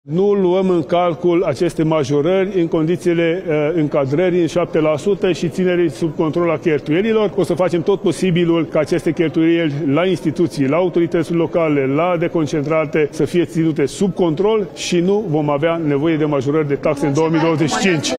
În bugetul pe acest an, nu e loc pentru indexarea pensiilor și a salariilor, le-a transmis ministrul Finanțelor, parlamentarilor din comisiile de specialitate care au avizat marți, 4 februarie, rând pe rând, bugetele ministerelor.
Ministrul Finanțelor, Tanczos Barna: Nu vom avea nevoie de majorări de taxe în 2025